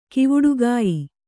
♪ kivuḍugāyi